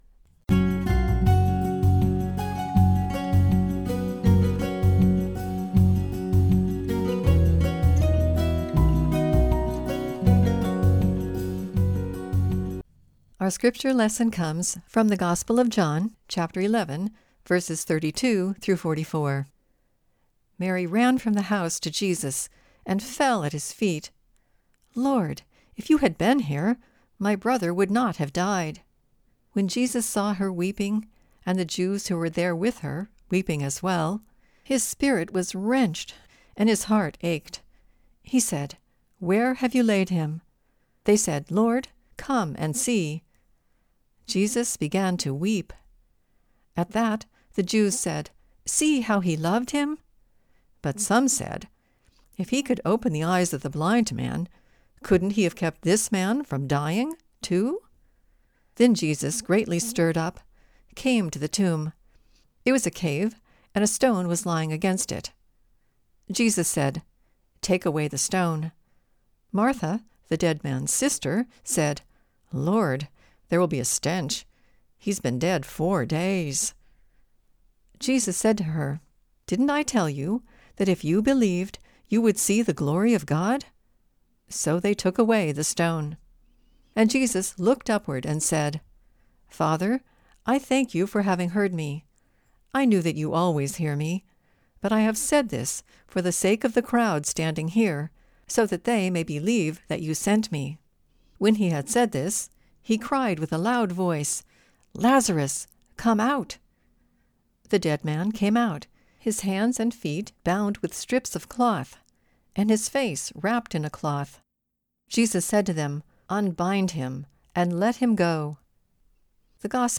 The Spoken Version